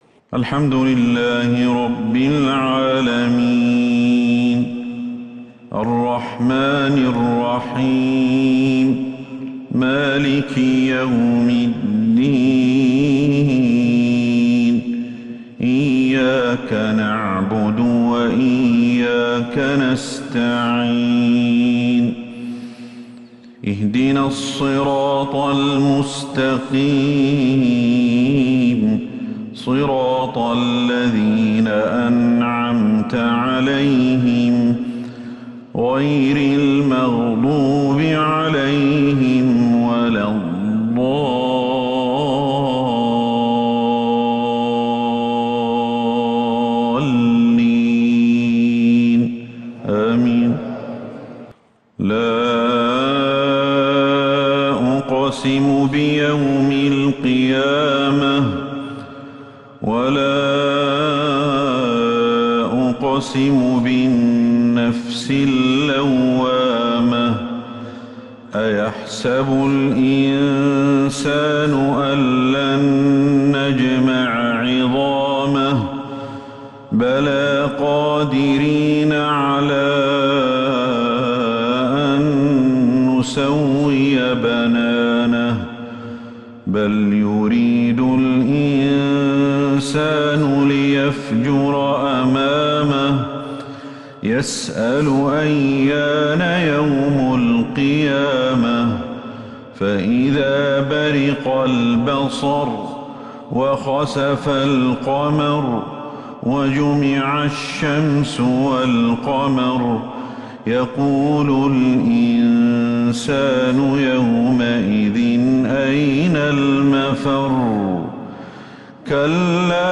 عشاء الإثنين 9 جمادى الأولى 1443هـ سورة {القيامة} كاملة > 1443 هـ > الفروض